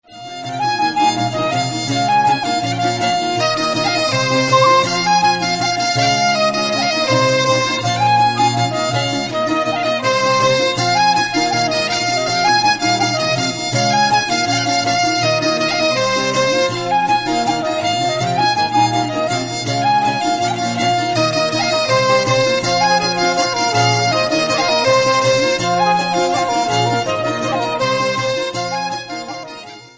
A breton group - Un groupe breton - A strolad breizad